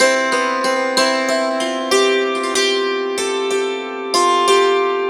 Dulcimer18_94_G.wav